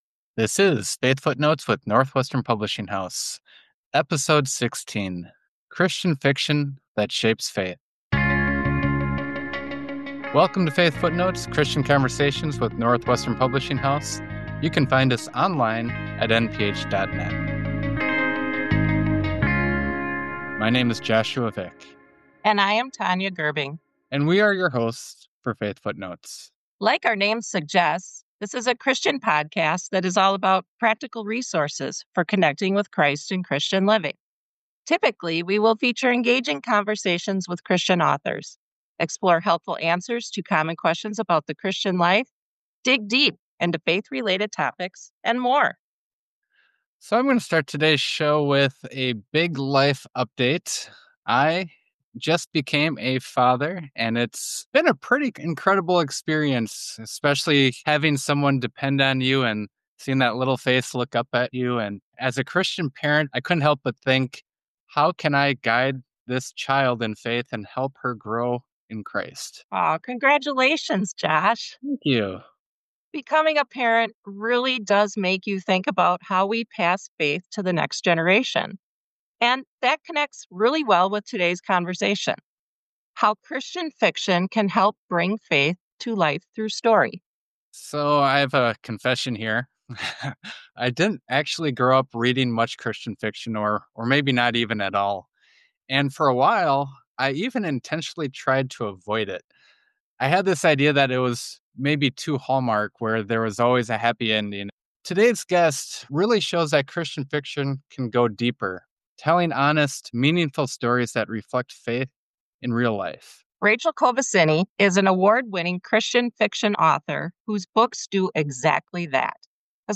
If you’ve ever wondered how a story can shape faith and deepen understanding of Scripture, this conversation offers practical insights and inspiration.